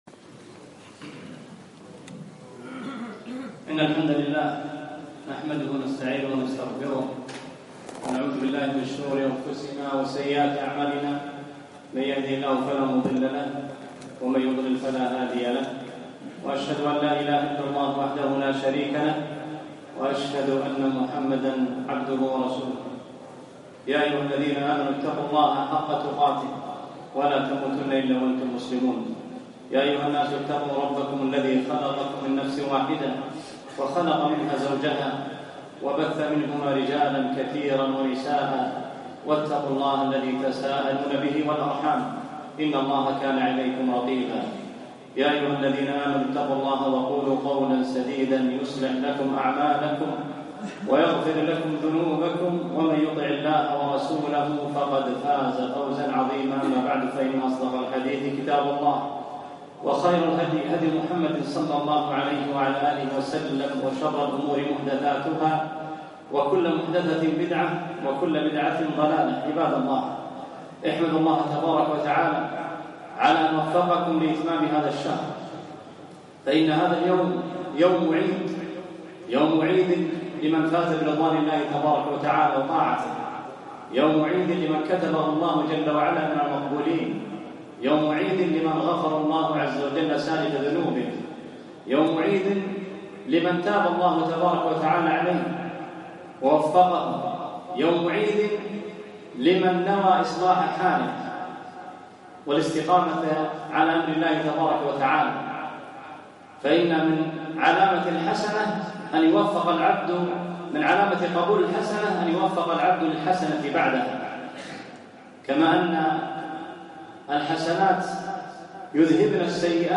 خطبة عيد الفطر عام ١٤٣٧